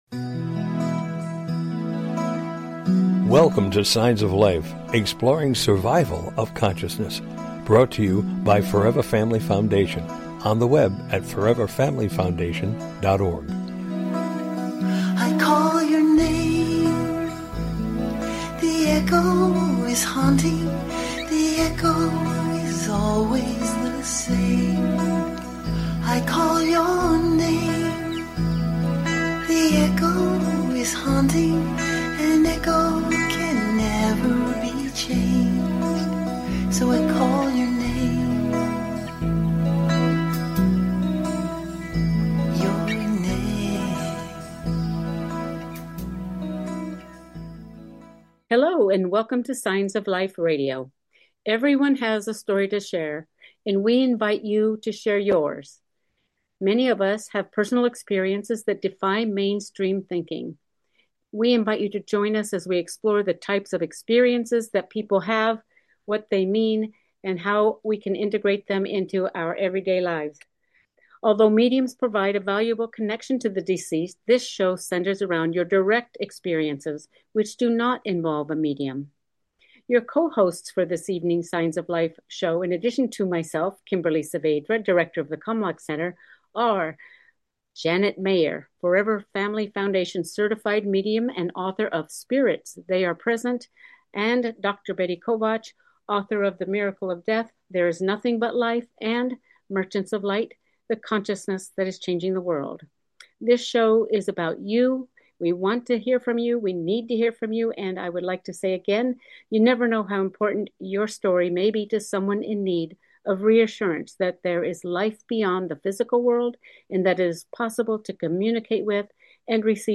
Talk Show Episode, Audio Podcast, Signs of Life - Personal Experiences and Personal Encounters and exploring the afterlife on , show guests , about Direct Signs,Physical Manifestations,exploring the afterlife,Personal Encounters,Language of Spirit,Spiritual Awakening,Reassurance,the Cluster Effect,Healing Unborn Connections, categorized as Kids & Family,Paranormal,Philosophy,Physics & Metaphysics,Psychology,Science,Self Help,Society and Culture,Spiritual